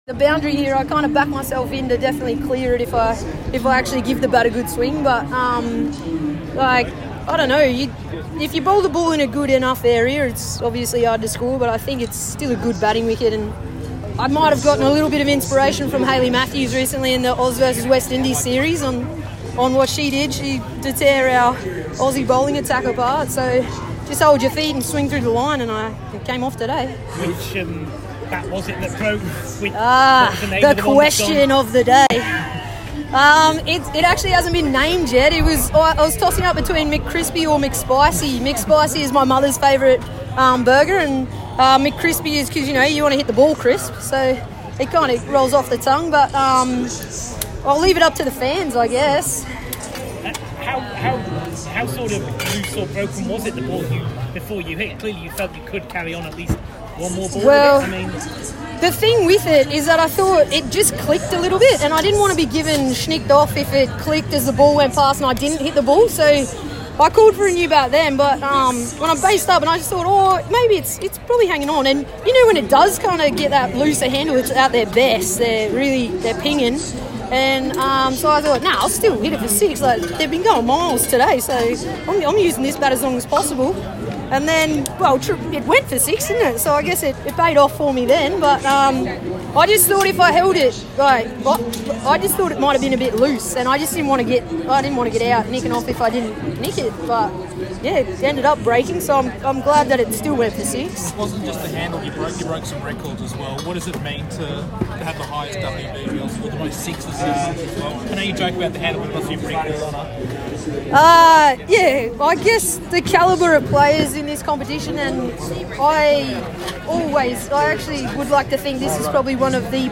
Brisbane Heat opener Grace Harris speaks with media following Brisbane’s win over the Perth Scorchers earlier today